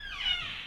random » door kick public bathroom door hit open slam echo
描述：door kick public bathroom door hit open slam echo
标签： kick public open door slam hit bathroom
声道立体声